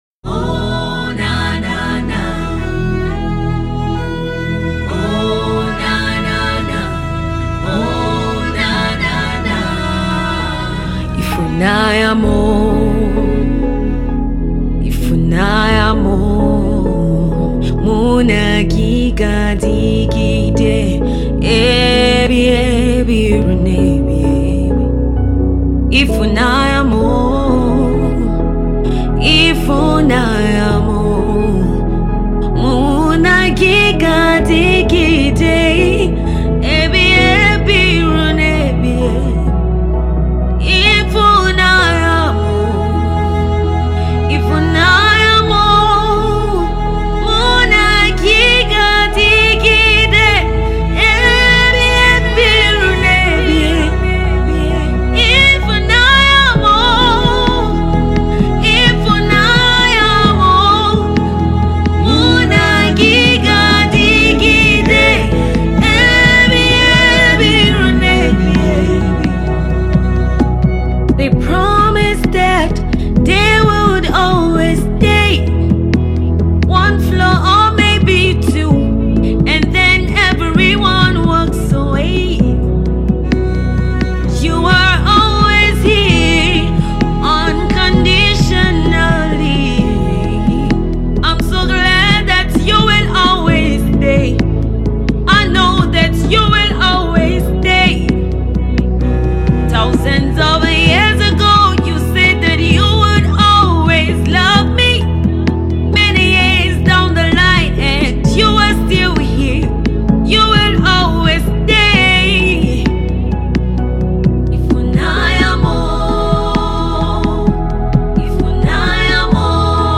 a love song